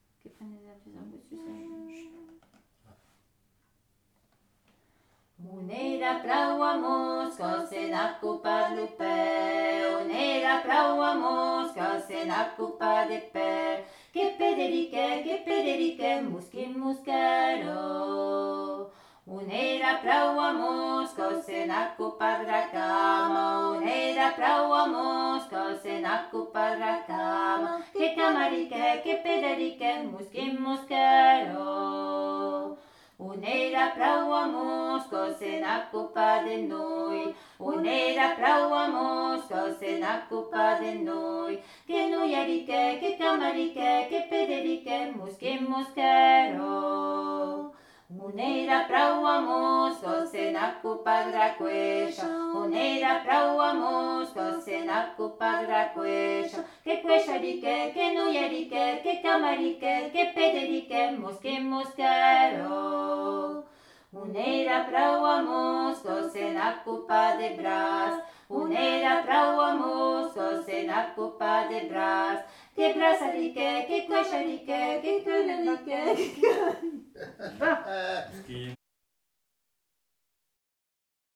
Aire culturelle : Bigorre
Lieu : Ayros-Arbouix
Genre : chant
Effectif : 2
Type de voix : voix de femme
Production du son : chanté
Descripteurs : polyphonie